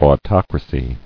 [au·toc·ra·cy]